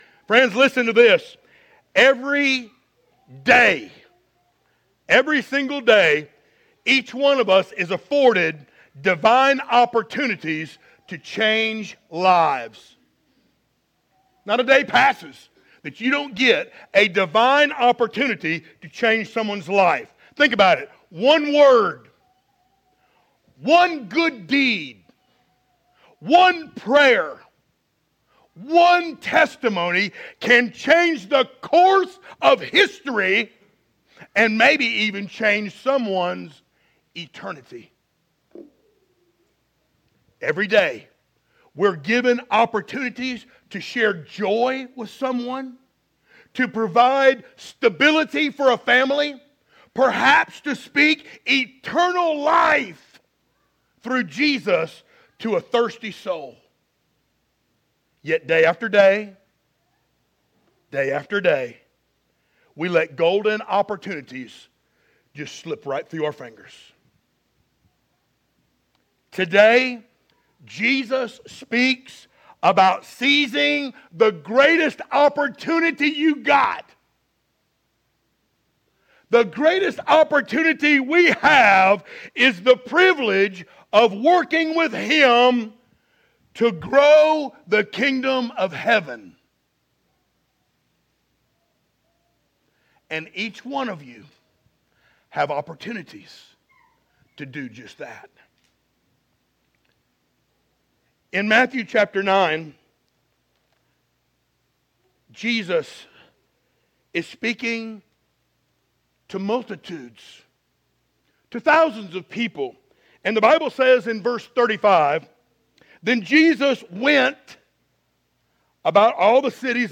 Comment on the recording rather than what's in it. Matthew 9:35-38 Service Type: Sunday Morning Download Files Notes « “Phenomenal Faith” The Faithful Few »